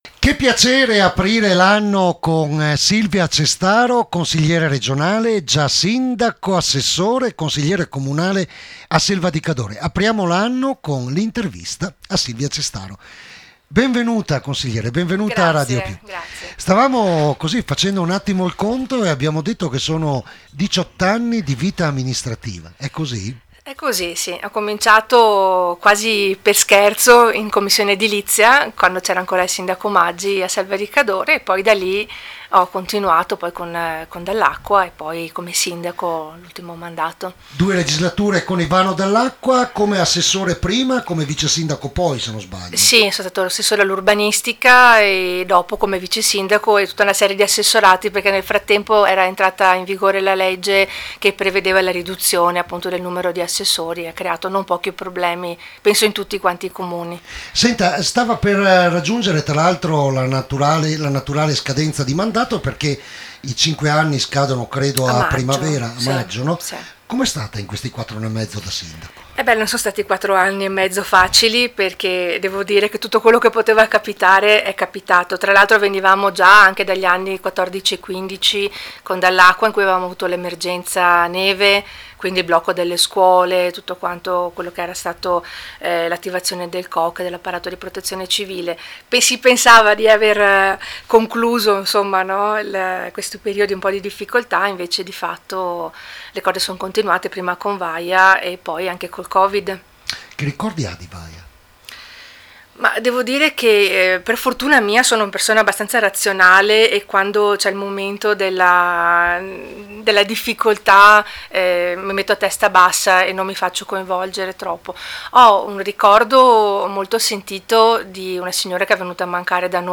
RADIO PIU’ La prima intervista del 2021 è al consigliere regionale Silvia Cestaro, già sindaco di Selva di Cadore.